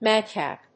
音節mád・càp 発音記号・読み方
/ˈmæˌdkæp(米国英語)/